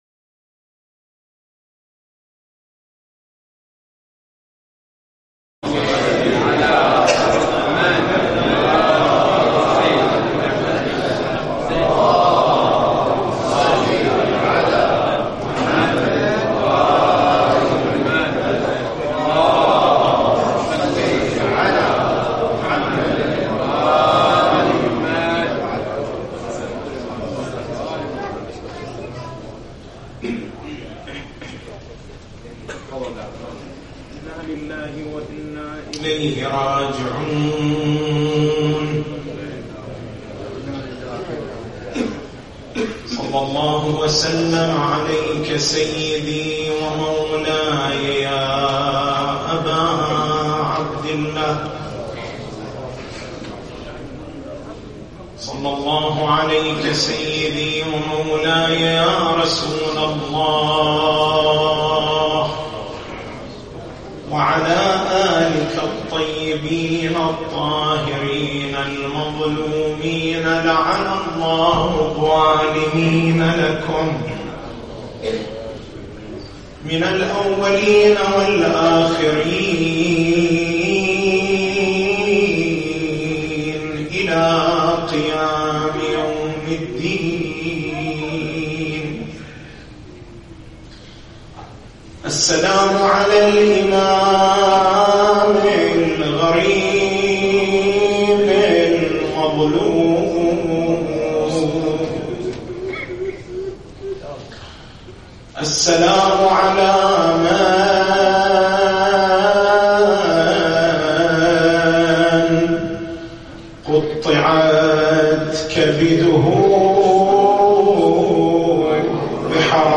تاريخ المحاضرة: 08/03/1441 نقاط البحث: ما هي التحديات التي واجهها الإمام العسكري (ع)؟